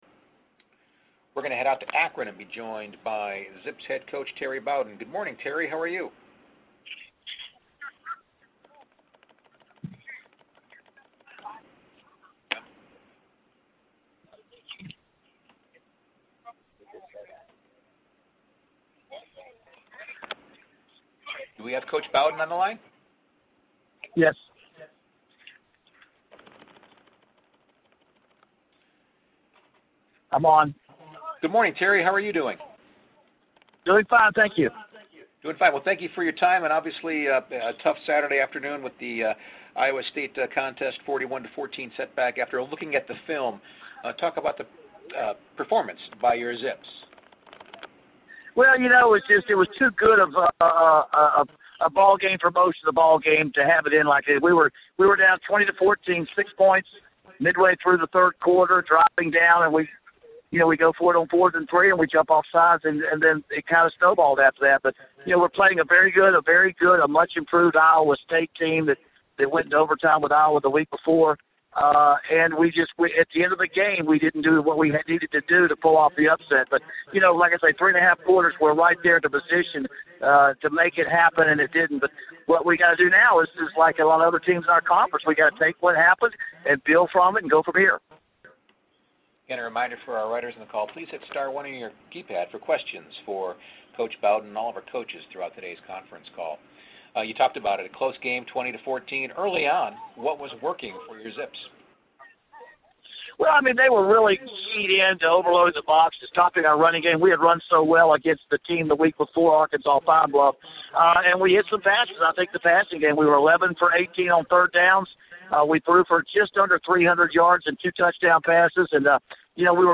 MAC Weekly Media Teleconference Audio